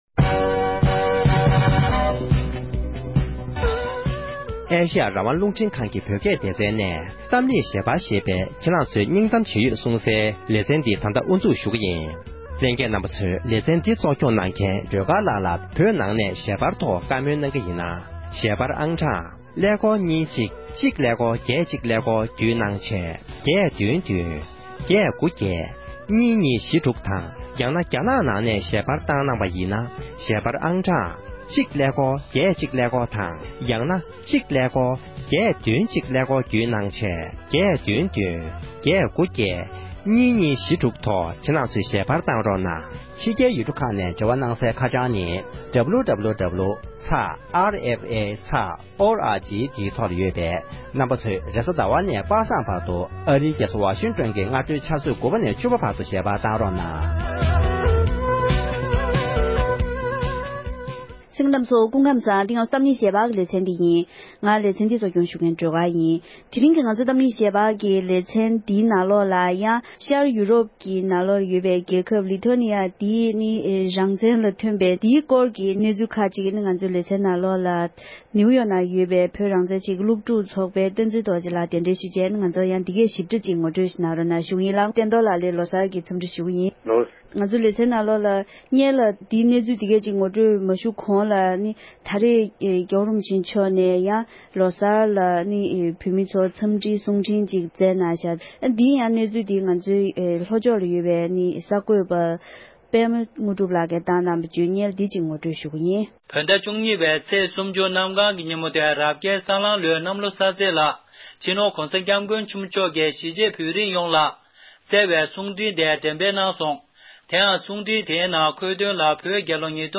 ལི་ཐོ་ནི་ཡ་ཡུལ་གྲུ་འདི་བཞིན་རང་དབང་དང་དམངས་གཙོའི་ལམ་ཐོག་ལ་སྐྱོད་པའི་རྒྱུད་རིམ་དང་བོད་ཀྱི་གནས་སྟངས་སྐོར་བགྲོ་གླེང་ཞུས་པ།